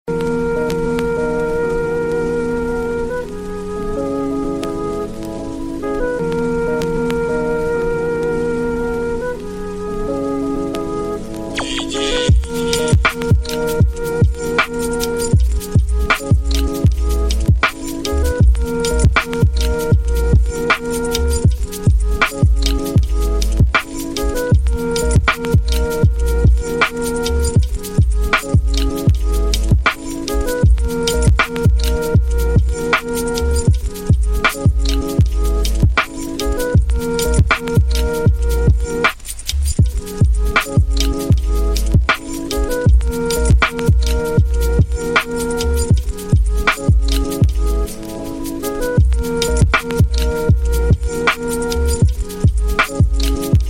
Pluie Nocturne : Ambiance Seule
Dans focus définition, tous les épisodes commencent par les publicités afin que rien ne vienne troubler ton moment d’écoute, parce que tu mérites un espace qui respire, un espace où même le silence devient un allié.